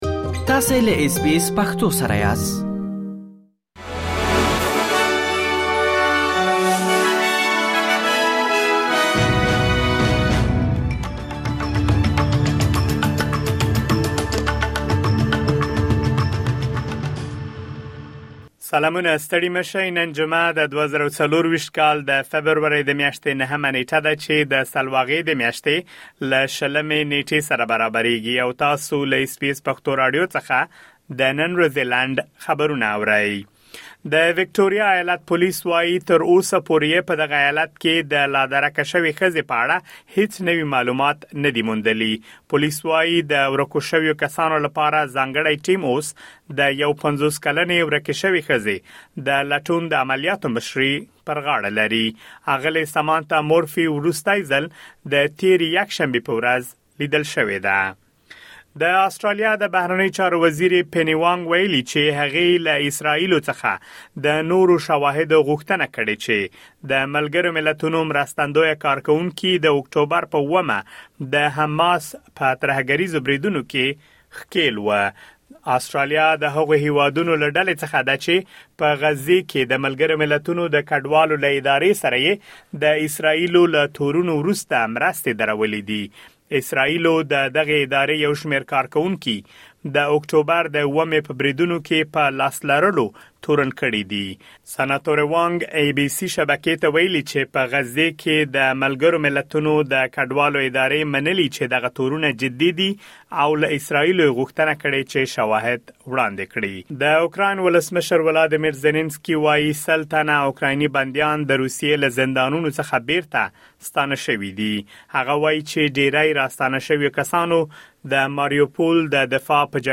د اس بي اس پښتو راډیو د نن ورځې لنډ خبرونه |۹ فبروري ۲۰۲۴